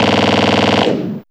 Index of /m8-backup/M8/Samples/Fairlight CMI/IIe/27Effects4
MGun2.wav